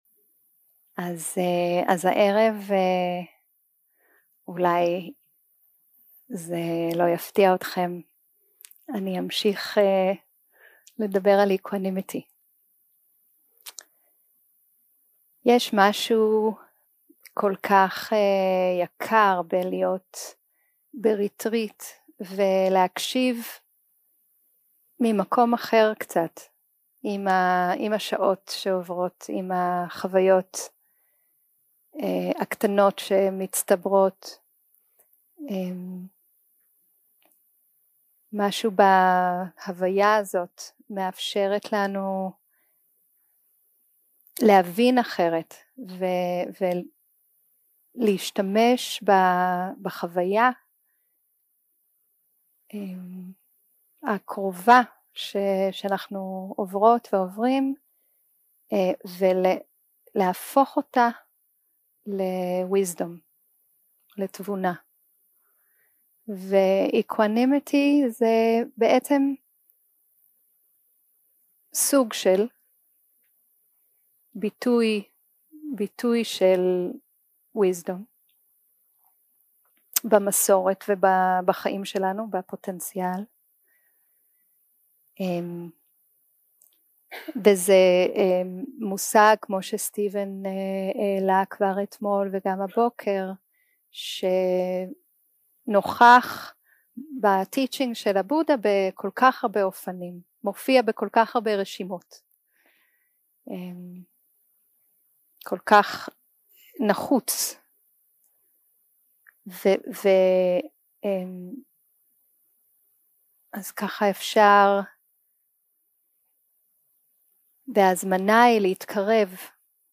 יום 2 - הקלטה 4 - ערב - שיחת דהרמה - אופקהא ושמונת הרוחות
Dharma Talks שפת ההקלטה